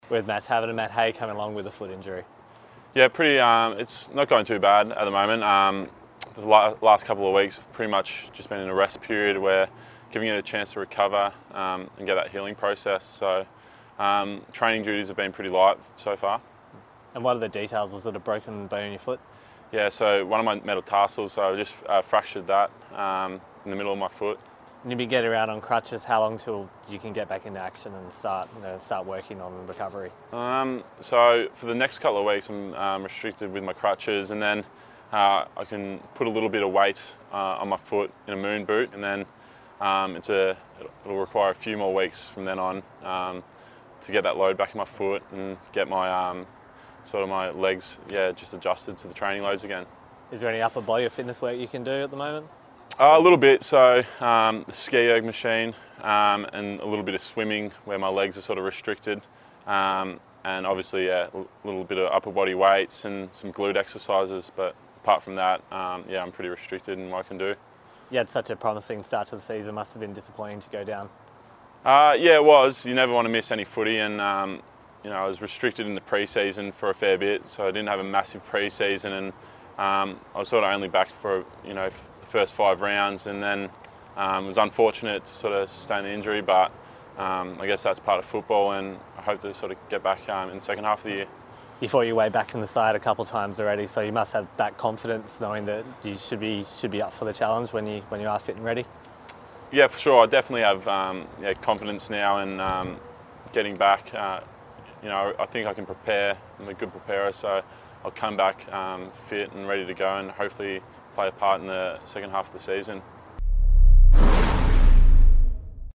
Matt Taberner Interview - 9 May 2018